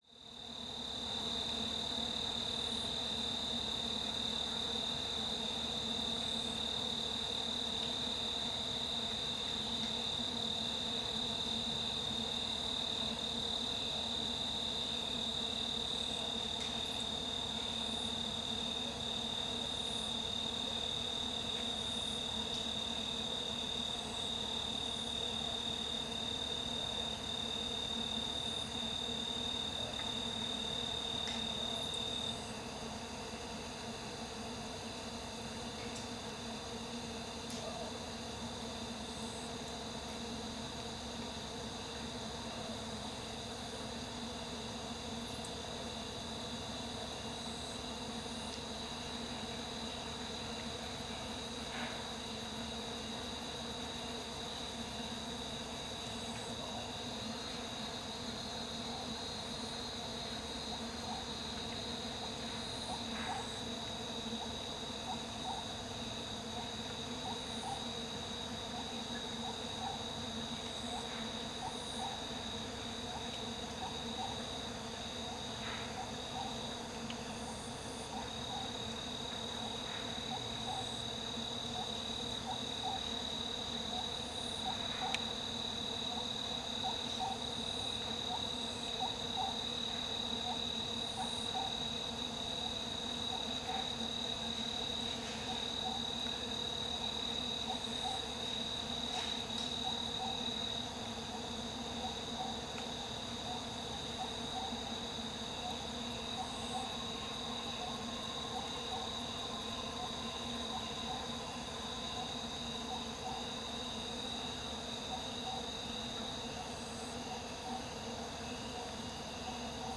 Field Recordings from the Northern India Jungles
No ‘foreign’ sounds penetrate the jungles and the area is not overflown by aircraft.
I set up to capture the jungle sounds as night settled in.
The hum of night insects starts taking over from bird calls heard during the day time. A Black-rumped Flameback (Dinopium benghalense) is first heard as well as a Lineated Barbet (Megalaima lineata). and the Common Hawk Cuckoo (Hierococcyx varius) easily recognized by its loud shrill calls in a repeated sequence each phrase increasing in pitch. Soon everything calms down and the calls of the Oriental Scops Owl (Otus sunia) and the Large-tailed Nightjar (Caprimulgus macrurus) appear to go on throughout the night.
Equipment used: Sennheiser MKH 8020, MKH 8040 and MKH 30 microphones, DPA 8011 hydrophones, Sound Devices 702 recorder.